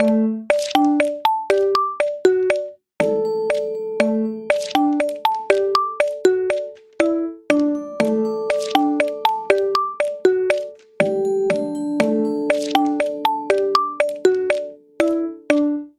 Стандартный рингтон